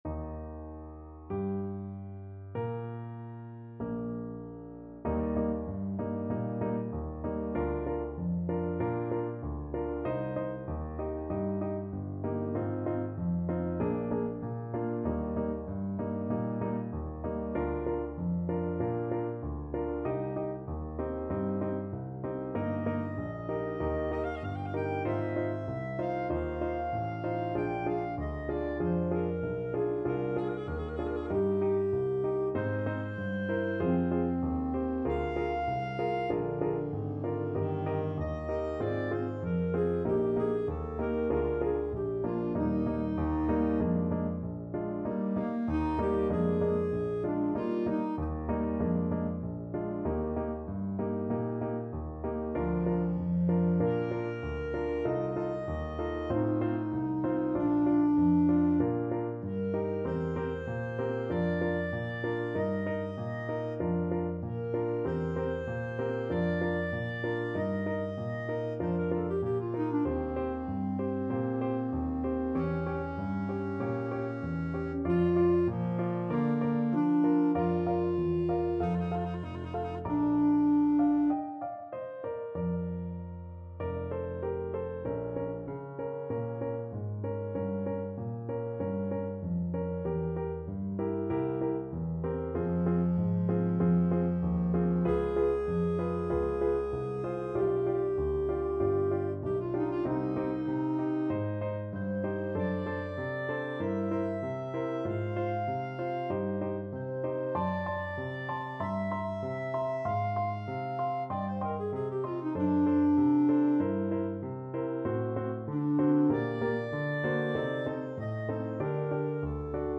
4/4 (View more 4/4 Music)
Adagio =48
Classical (View more Classical Flute-Clarinet Duet Music)